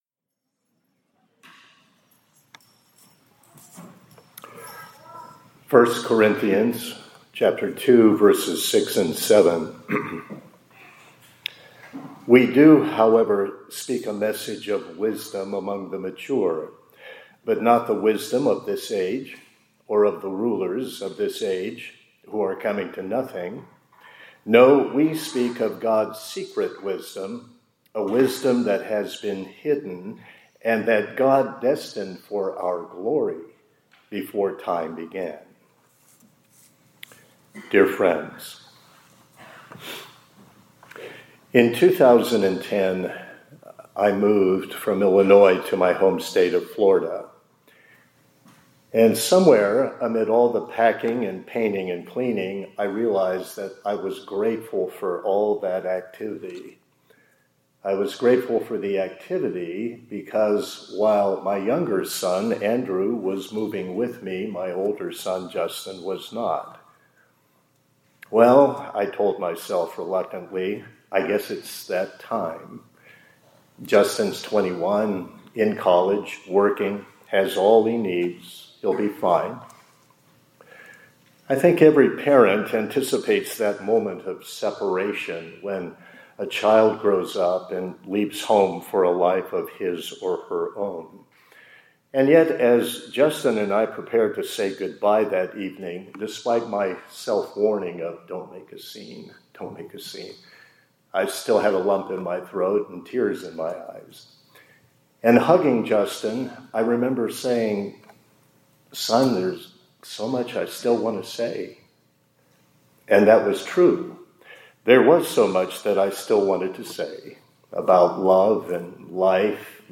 2025-09-16 ILC Chapel — The Lord Grants True Wisdom